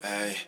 Vox
Aye.wav